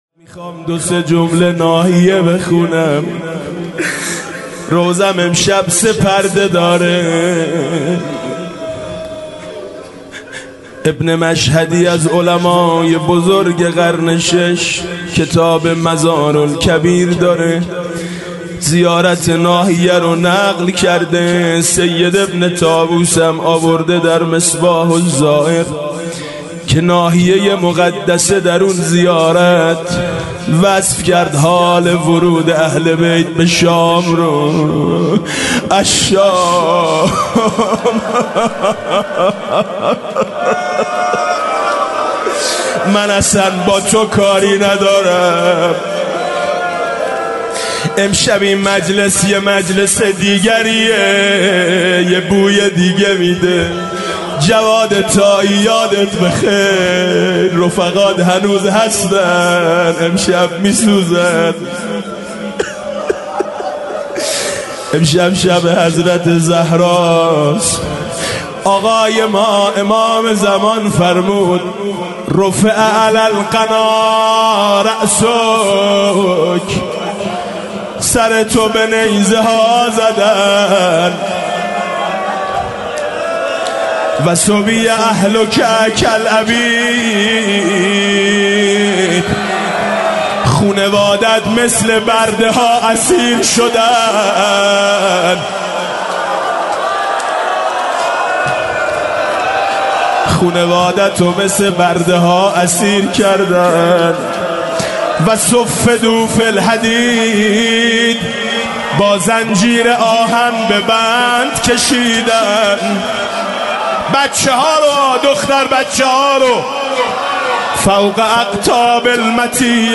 روضه شب سوم 1.mp3
روضه-شب-سوم-1.mp3